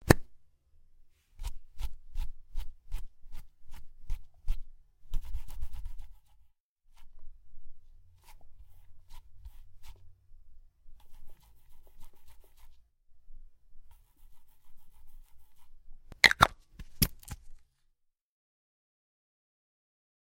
Звуки дезодоранта
Звук нанесения дезодоранта на кожу, мазь антиперспирант